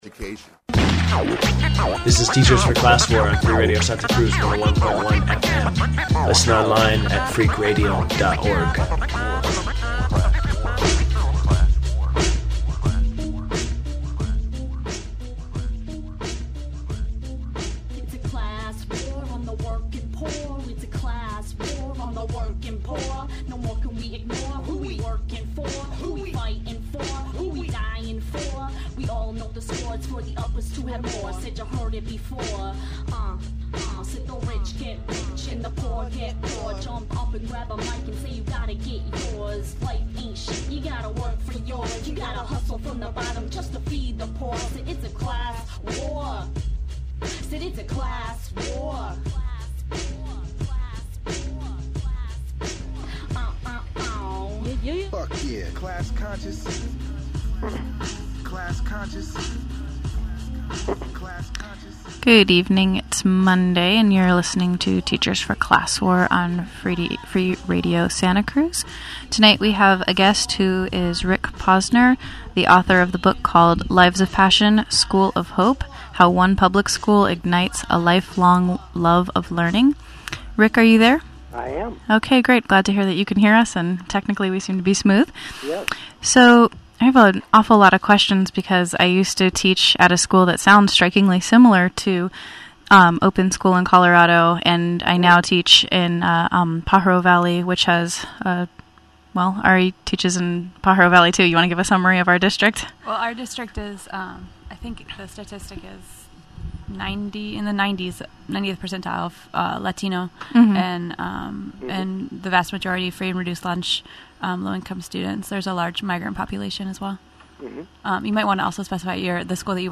59:42 mono MP3.